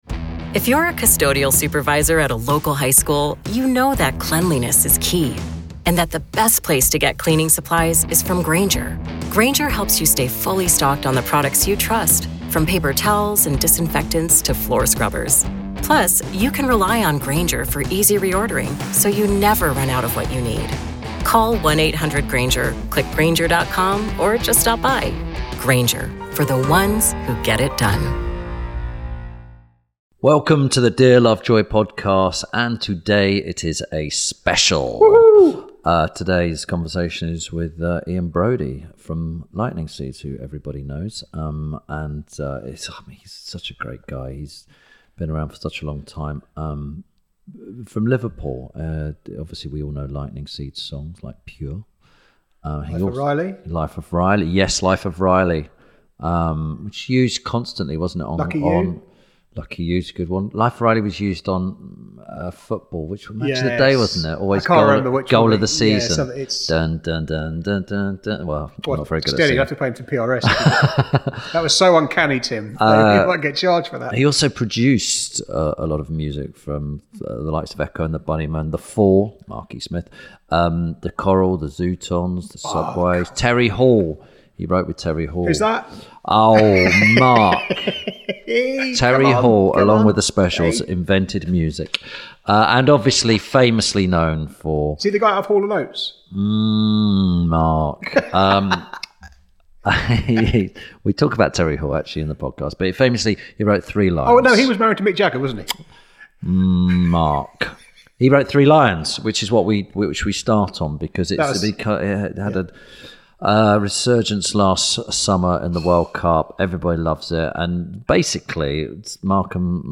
Ep. 104 – IAN BROUDIE - A Conversation With… – INTERVIEW SPECIAL
This week Tim Lovejoy talks to musician, songwriter, and producer Ian Broudie. They discuss the story behind Three Lions, his early punk days around Liverpool, Manchester, and London, and how he accidentally started out as a producer.